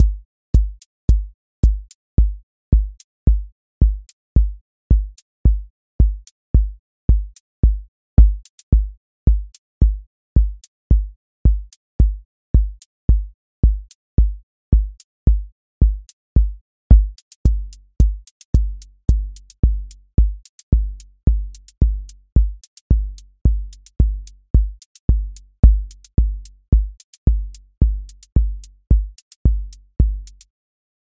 house four on floor 30s
voice_kick_808 voice_hat_rimshot voice_sub_pulse